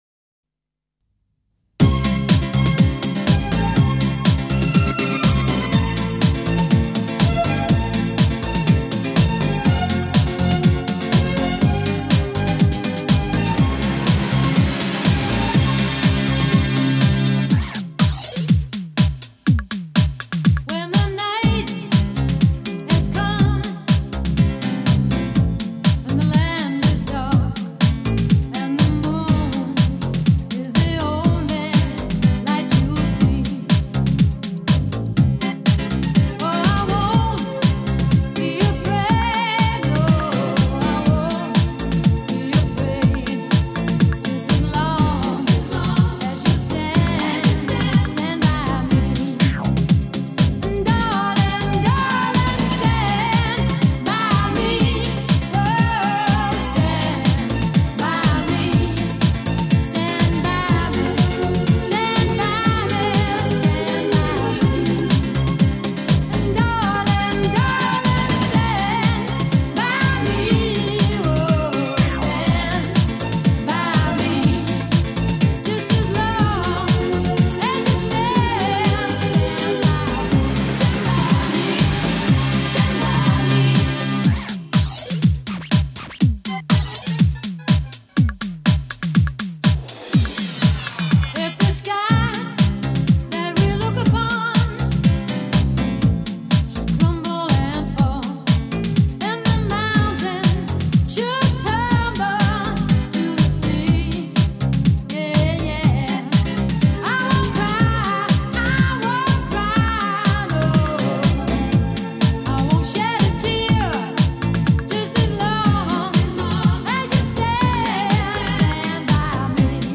开创DISCO新纪元。
本张专辑百分之百舞林脱口秀佳作，一语惊人道出舞情万种。